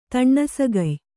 ♪ taṇṇasagay